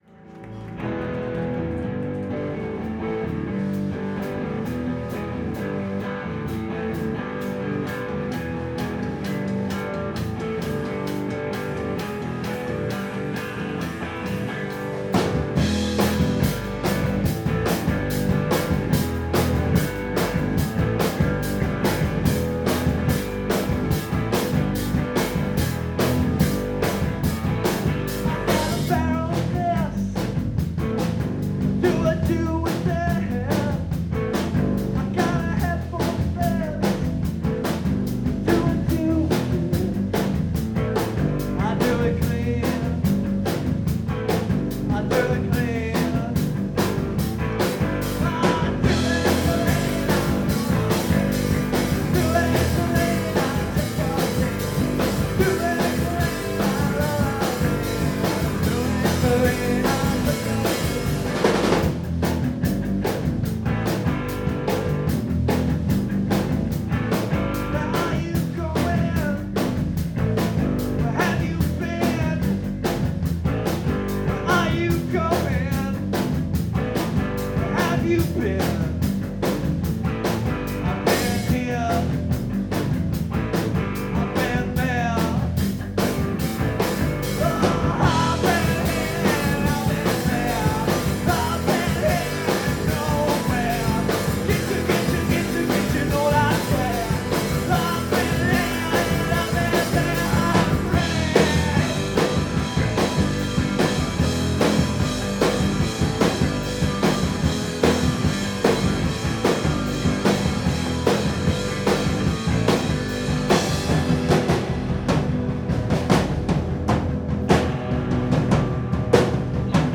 high octane cover